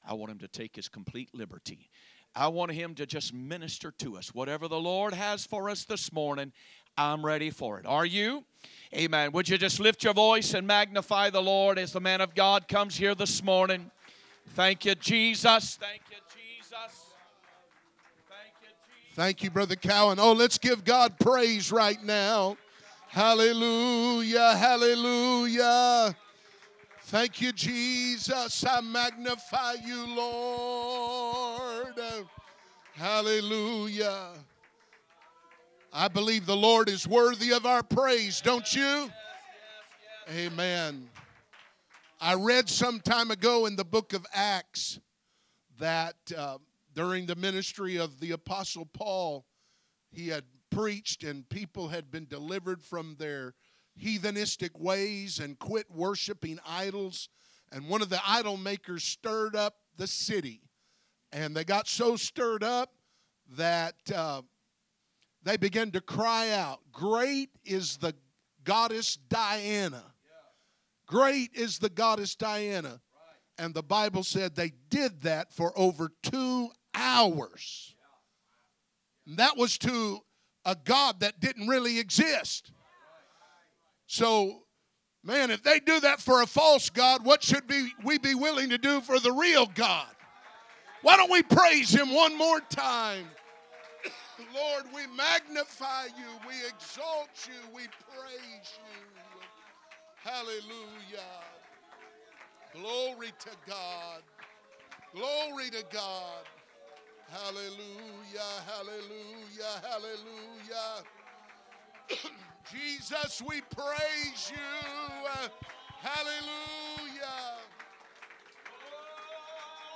Sunday Morning Service